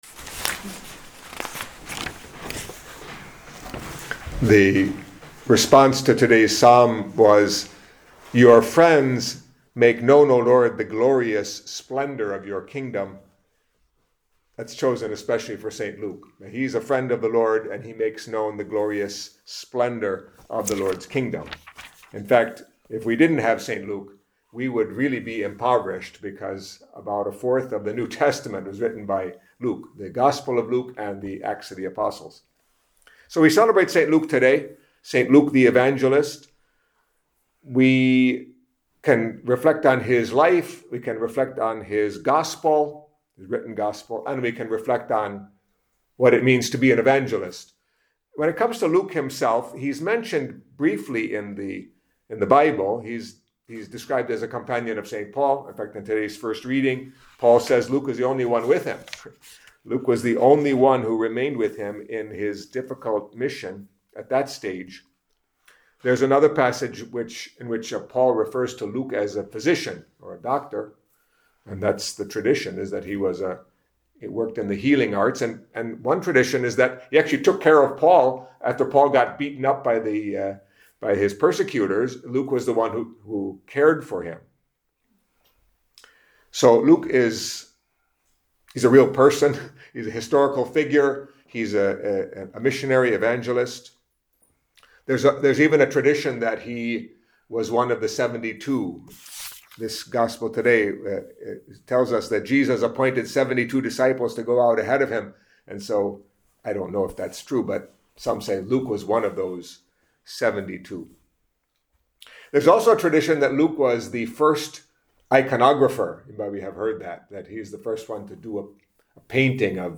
Catholic Mass homily for the feast of St. Luke, Evangelist